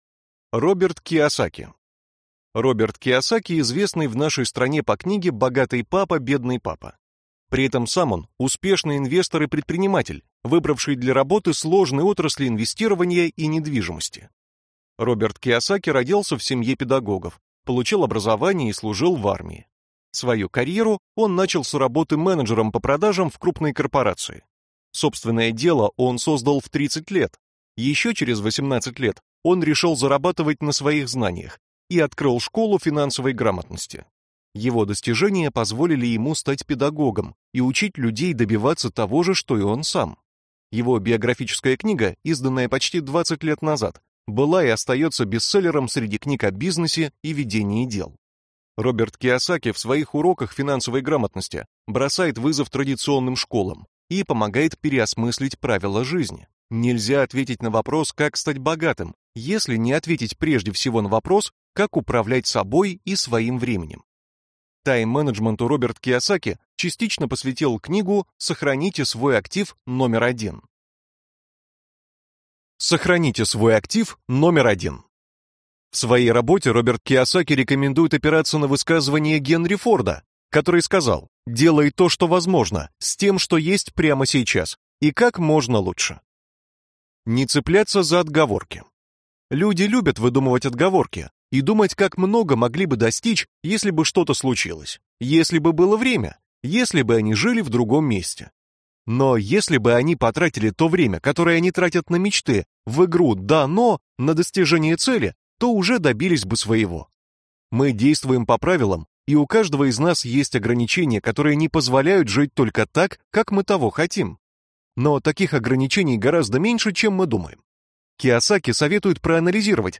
Аудиокнига Тайм-менеджмент | Библиотека аудиокниг